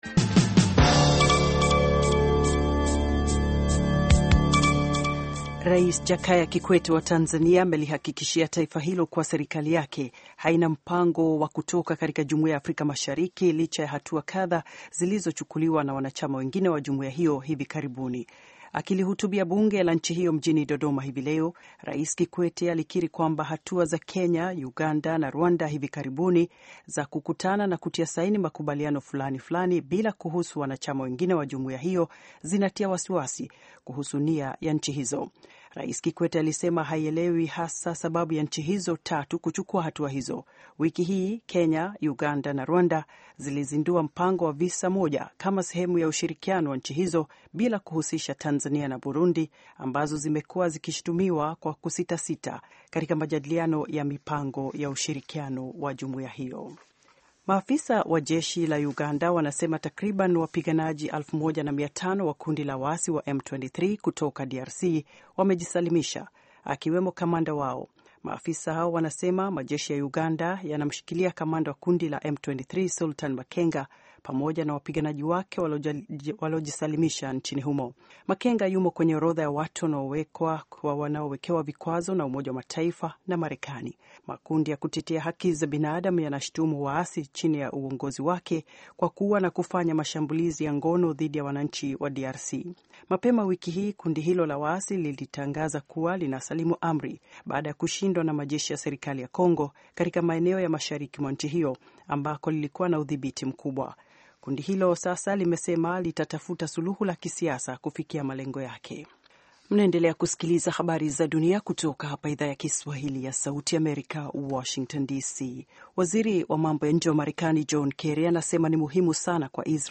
Taarifa ya Habari VOA Swahili - 6:05